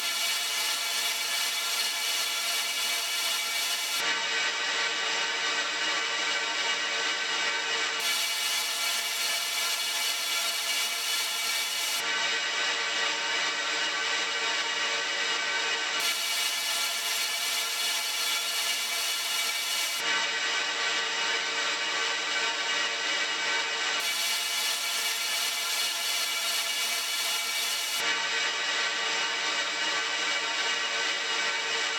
These are the chord sounds I was then able to create by resampling small, textual parts from the granulator into the Ableton sampler.
chords-2.wav